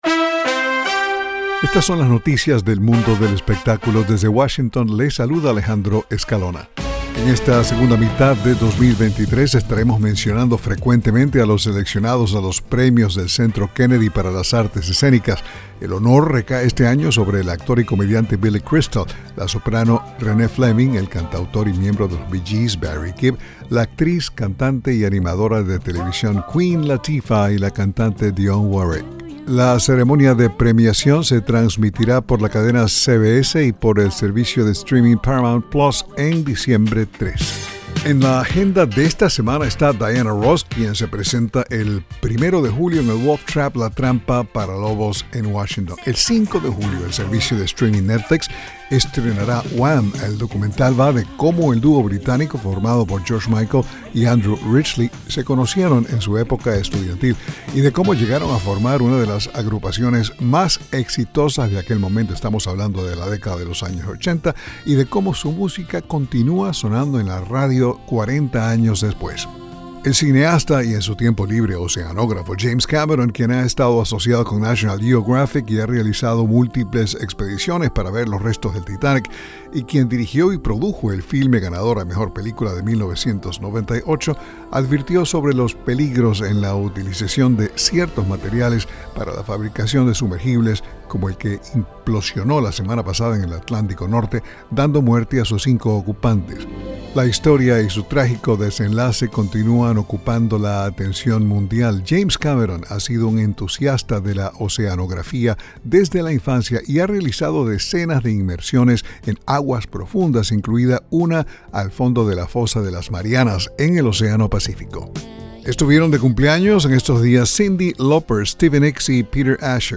Son las noticias del mundo del espectáculo